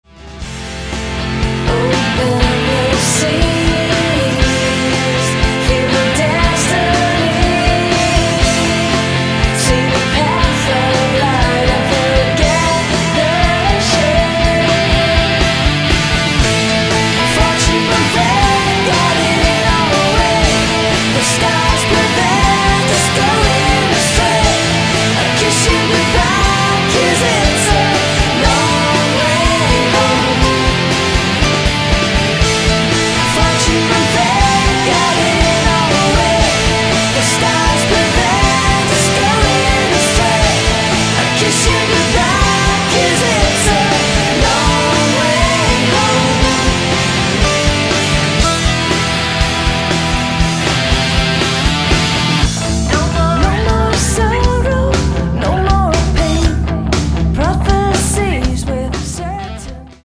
Genre: metal moderne